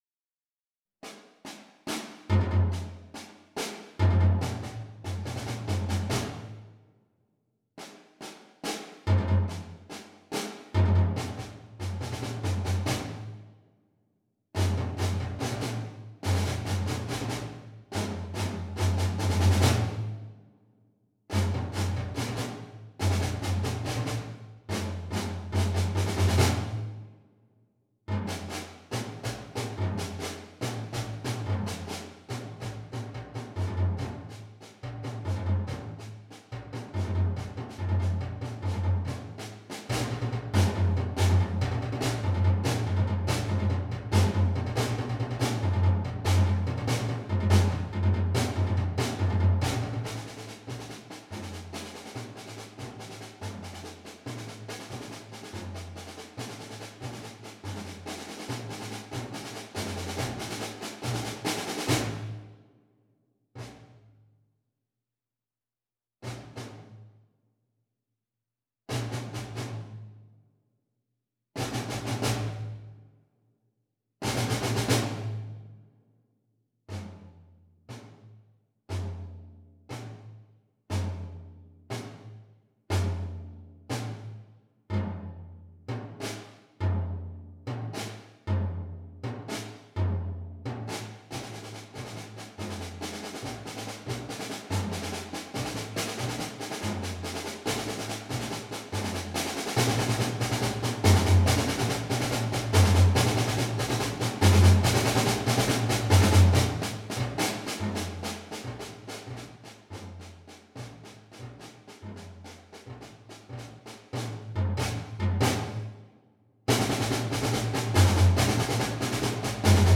Voicing: Percussion Trio